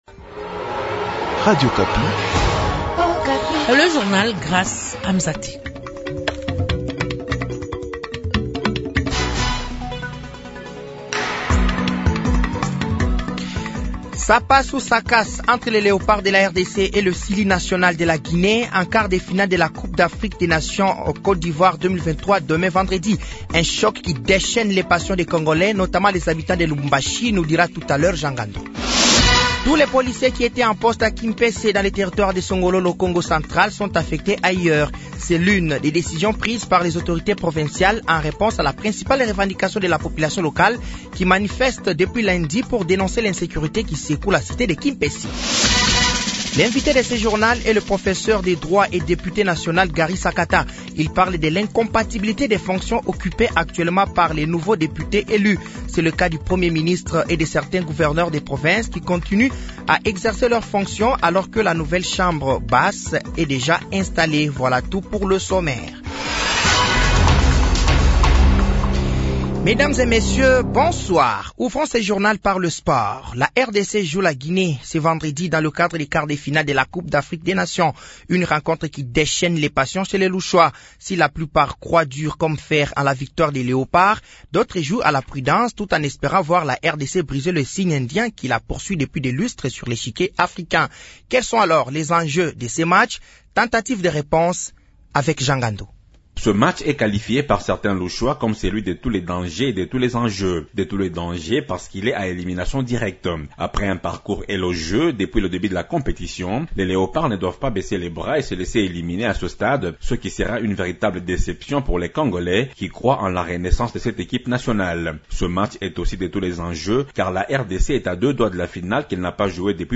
Journal français de 18h de ce jeudi 1er février 2024